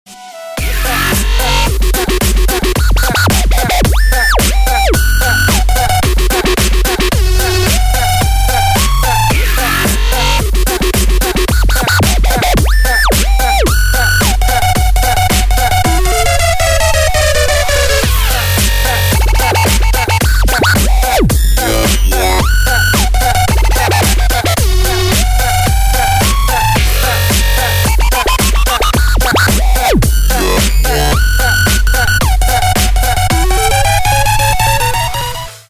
• Качество: 128, Stereo
громкие
dance
Electronic
EDM
электронная музыка
без слов